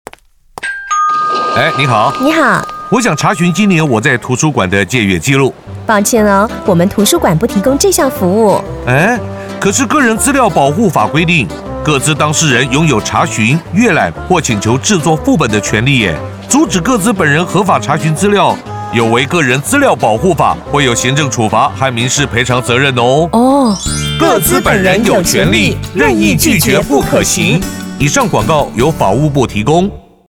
當事人權利篇-國語﹙公益錄音廣告﹚.mp3 ( 檔案下載)